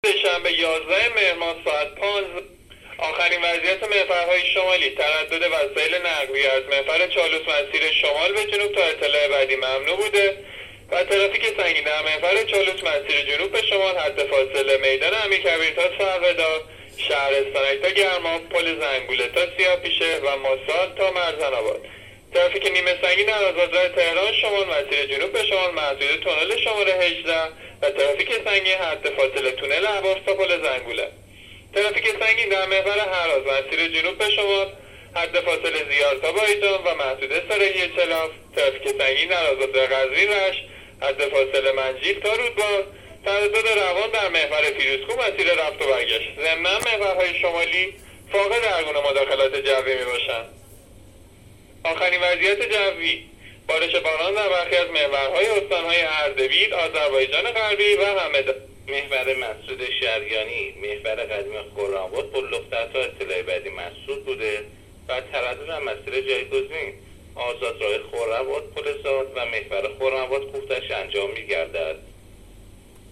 گزارش رادیو اینترنتی از آخرین وضعیت ترافیکی جاده‌ها تا ساعت ۱۵ یازدهم مهر؛